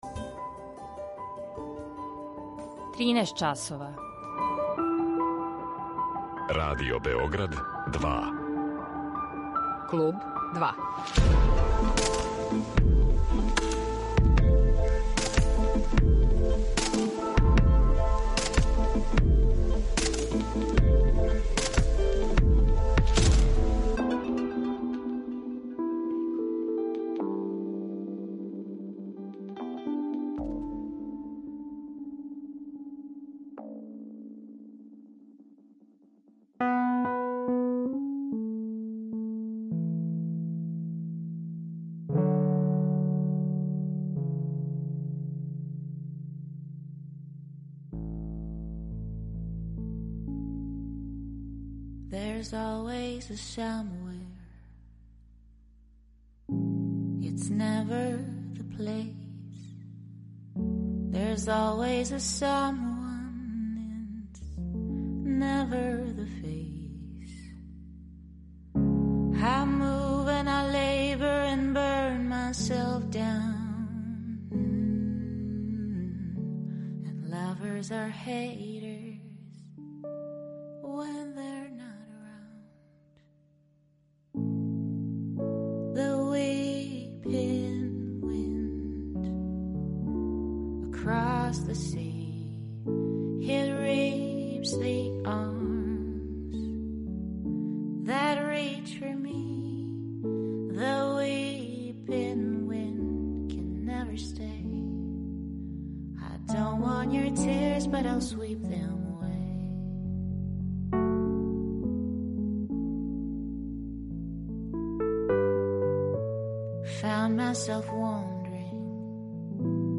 Наравно, као и тад, тако ћемо и данас слушати неке нове и нешто старије песме.